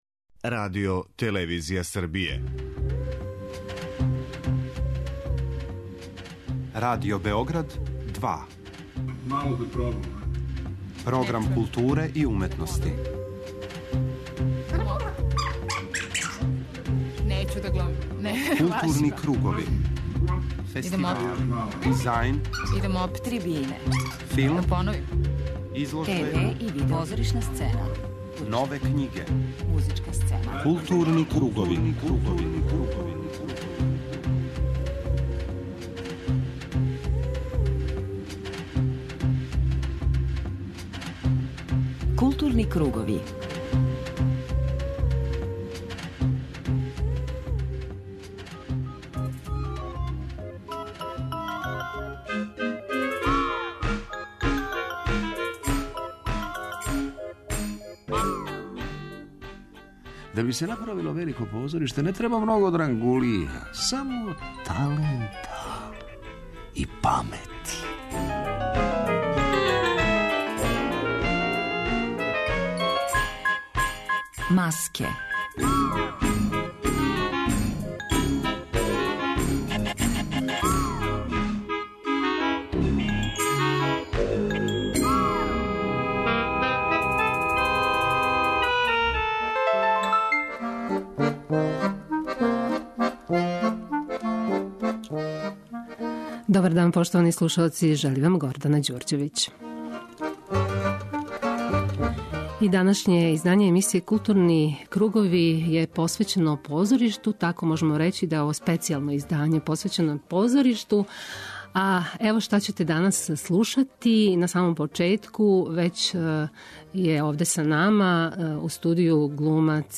У наставку емисије чућете и најзанимљивије разговоре остварене у протеклој години.